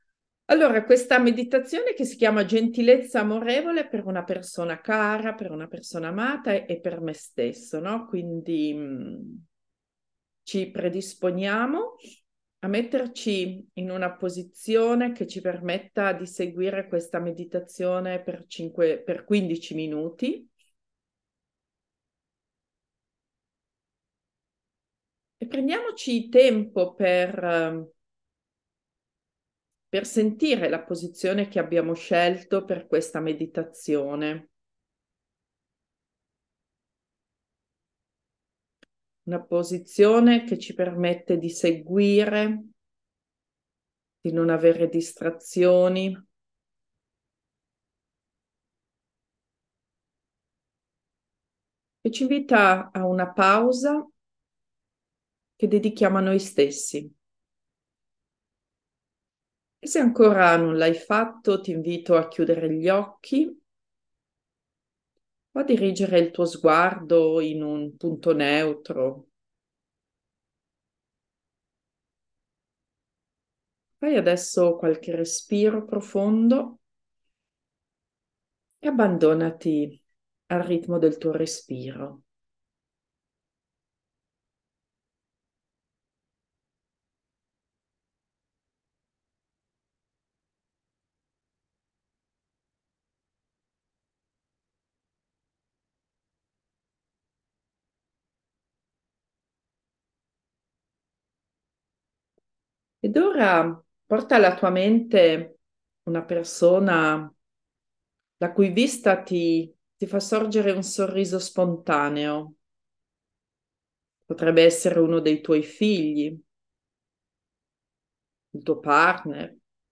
Le meditazioni sono state registrate in diretta durante i corsi con i genitori. Possono pertanto contenere alcune imperfezioni, ma hanno anche l’energia di un gruppo di genitori che medita assieme!